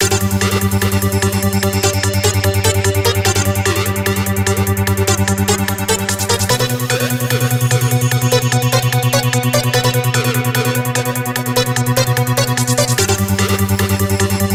trimmed to 29.5 seconds and faded out the last two seconds